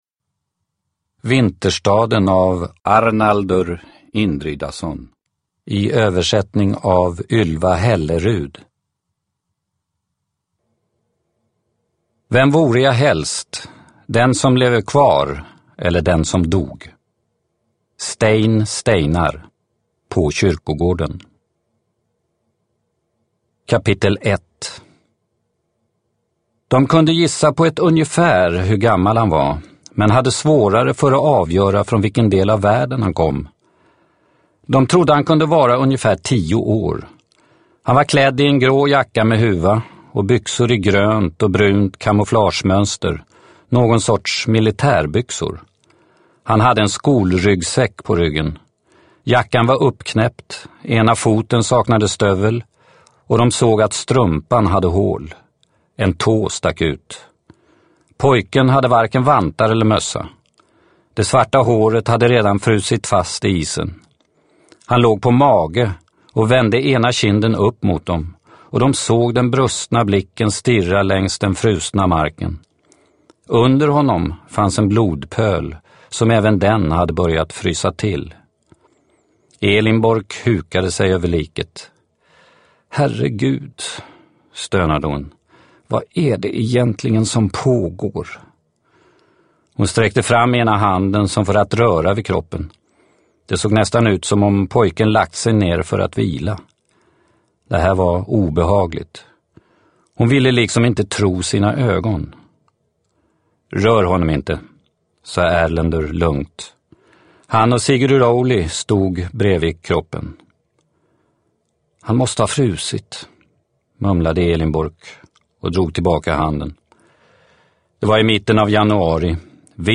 Vinterstaden – Ljudbok – Laddas ner